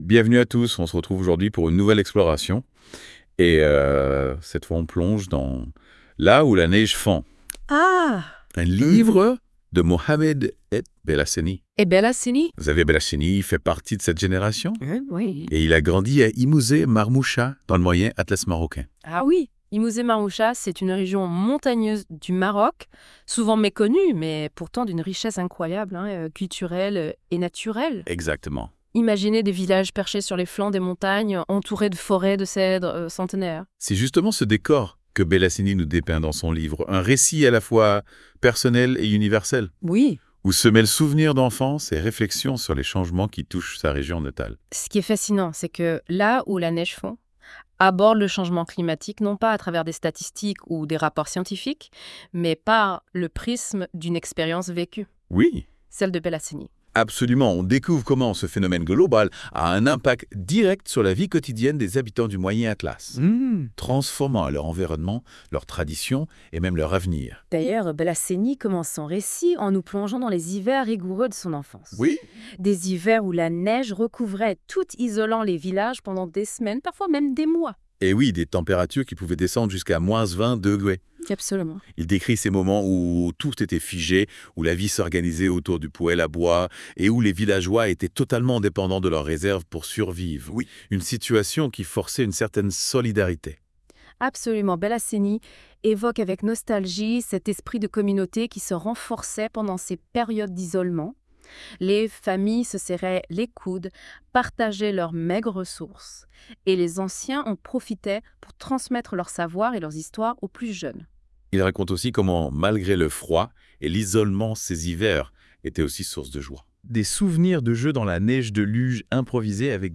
Là où la Neige Fond - Podcast-débat.wav (27.86 Mo)